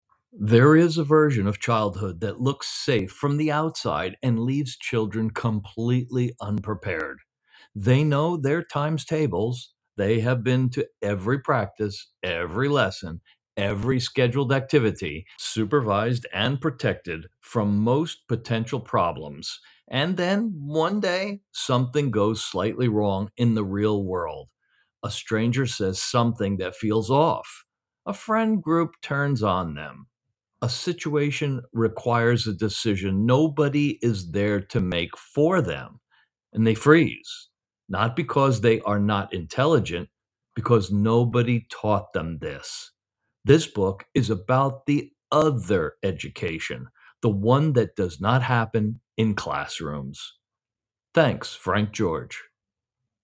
Male
Adult (30-50)
Warm, inviting, friendly, rich, authoritative, narration, clear, technical, commercial, soothing, calm, confident, upbeat, energetic, fun, punchy, trustworthy, credible, e-learning, audiobook, empathetic, Christian, announcer, radio, tv, corporate, educated, medical,
All our voice actors have professional broadcast quality recording studios.